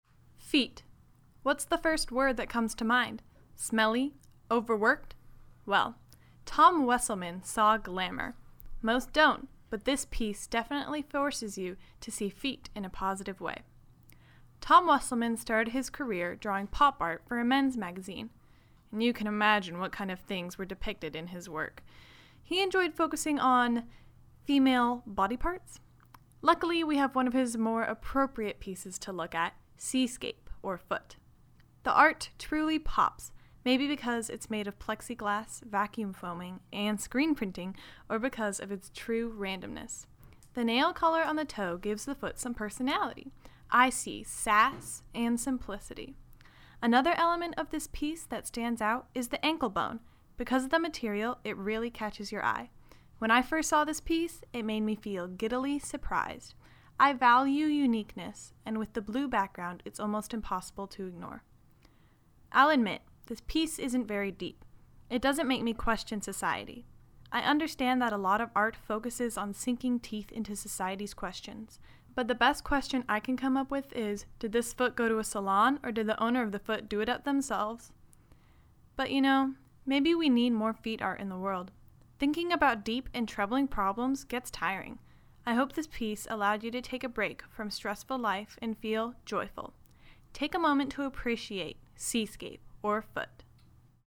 Audio Tour – Bulldog Art Tour